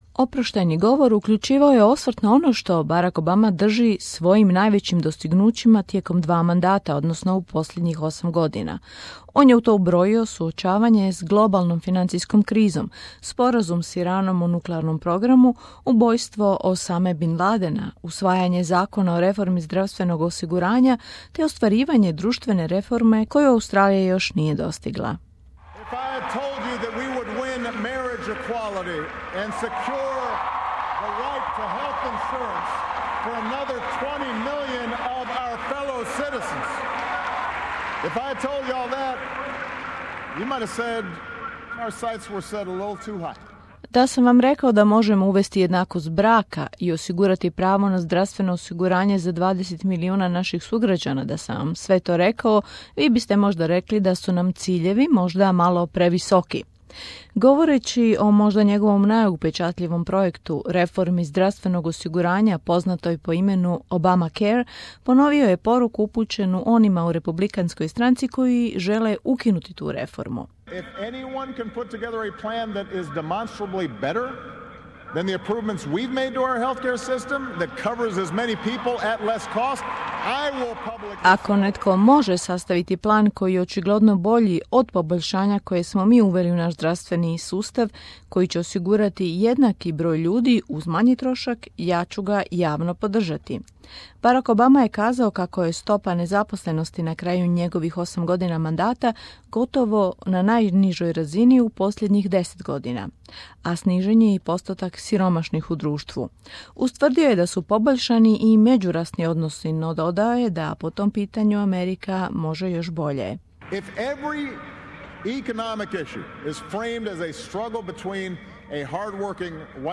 Oproštajni govor predsjednika Obame
Američki predsjednik Barak Obama u svom je oproštajnom govoru pozvao Amerikance da čuvaju demokraciju. Tijekom govora koji je održao u Chicagu Obama je upozorio da je demokracija ugrožena kad god građani pomisle da je ona neupitna. Kao tri najveće prijetnje demokraciji u Sjedinjenim Državama Obama je naveo ekonomsku nejednakost, rasne podjele i sklonost ljudi da se zatvore u svoje privatne sfere.